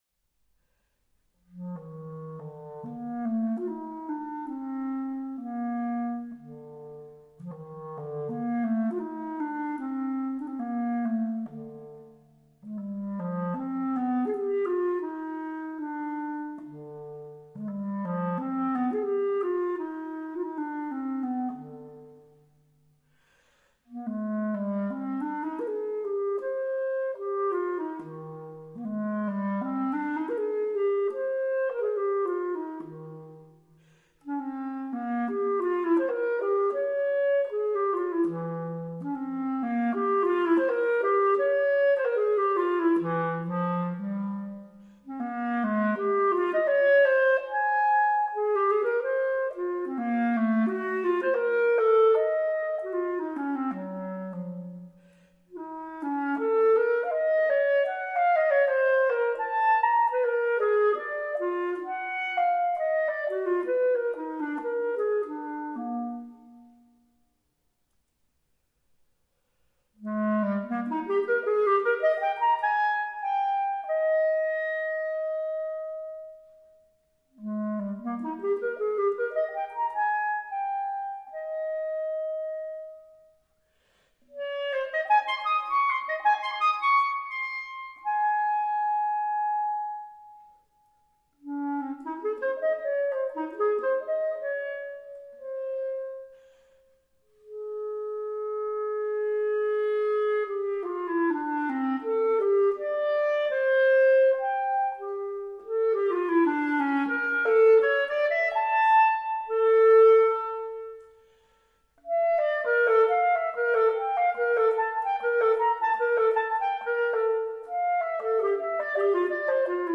per clarinetto solo
clarinetto
Pianoforte